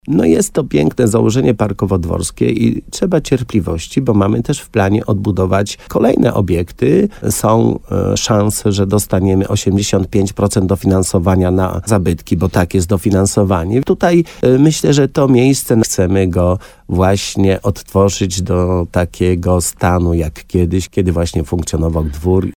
Jak mówi wójt, Leszek Skowron, poszerzy to ofertę, którą już daje ten kompleks.